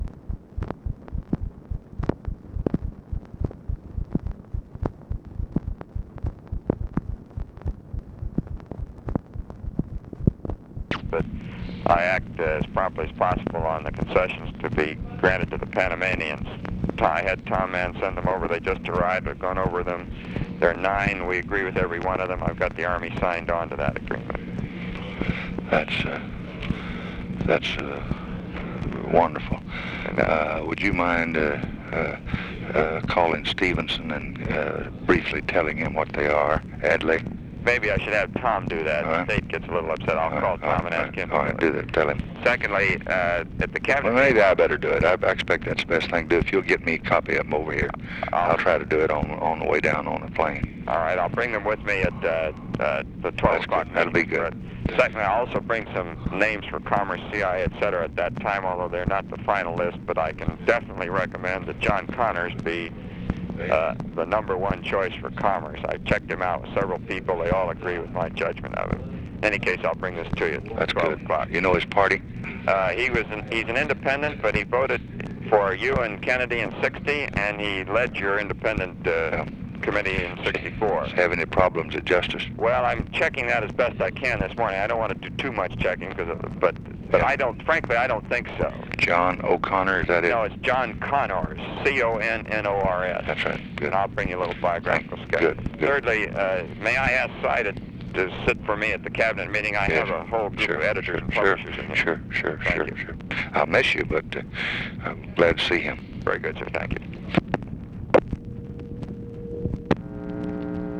Conversation with ROBERT MCNAMARA, November 19, 1964
Secret White House Tapes